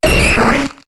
Cri d'Amonita dans Pokémon HOME.